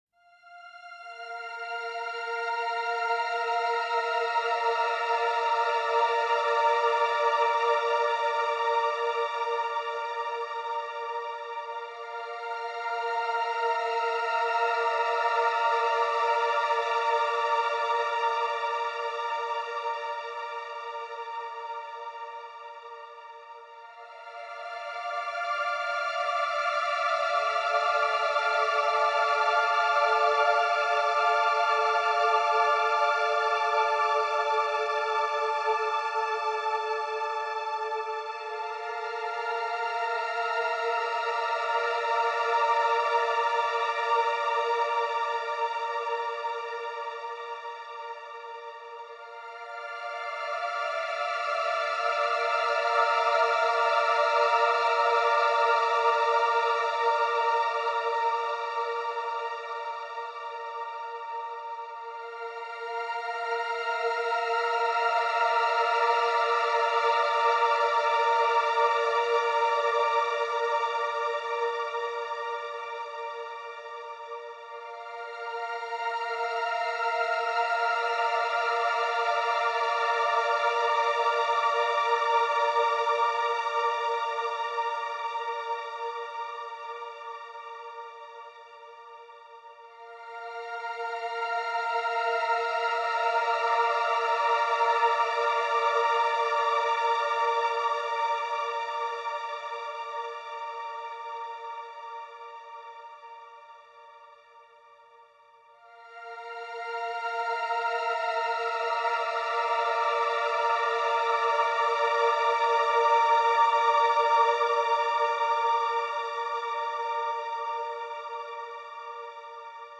Synth Drone Version